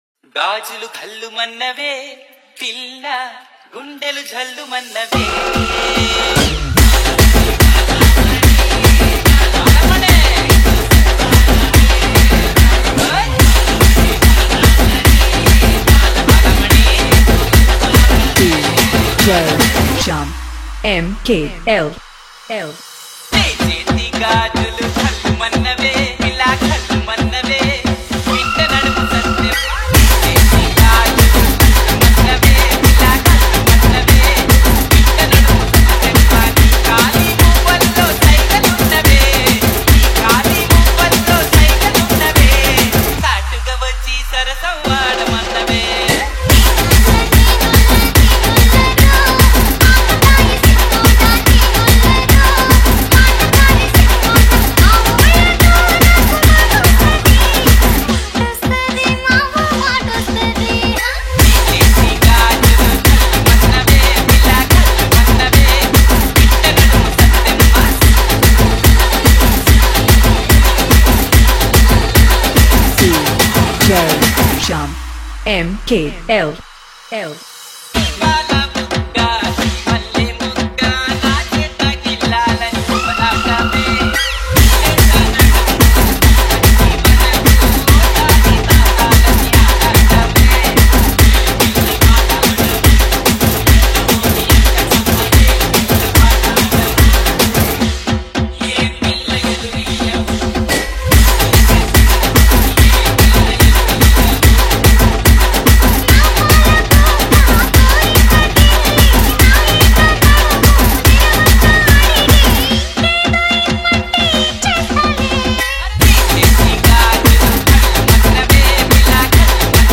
Old SONG REMIX